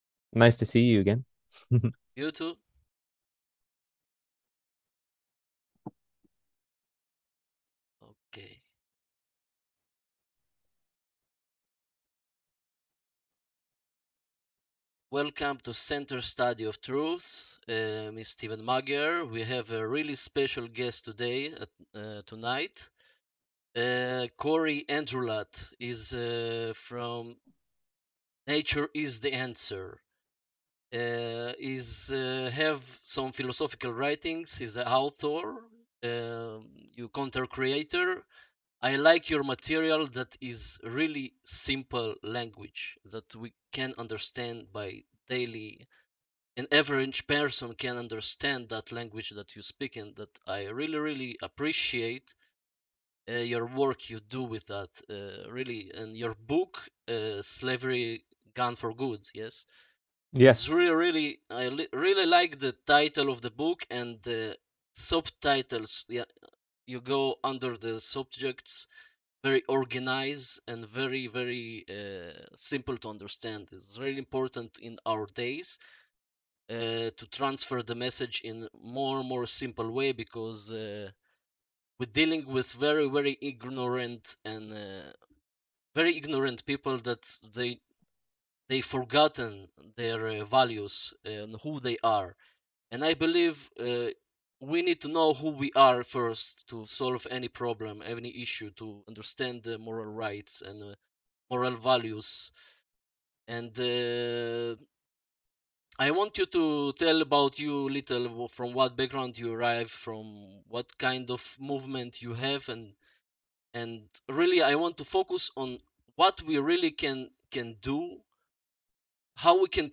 Natural Activism-How to end Slavery in Common Sense -Interview